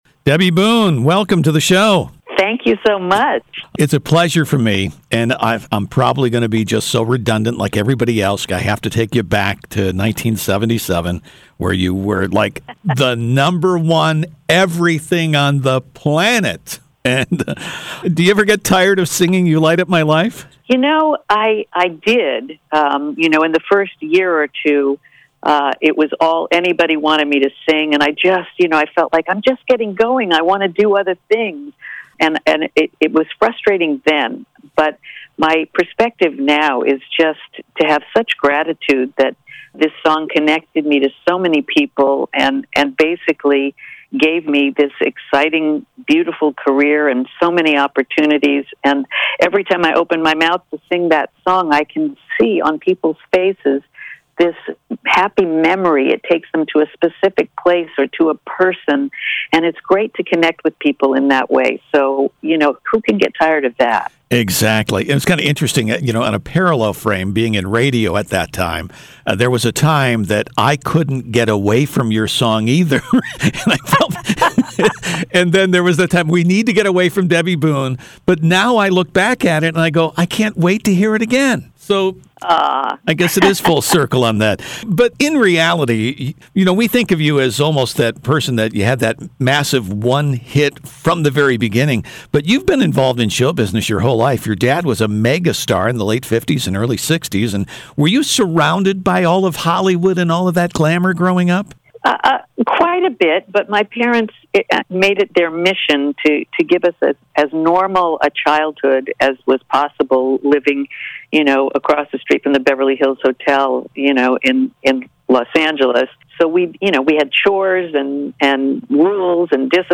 She also talks about the family’s embarassment when her father, squeeky-clean Pat Boone, recorded and performed a heavy-metal rock album late in his career. It’s a fun, entertaining and informative interview.
debbieboone-onair_mixdown1.mp3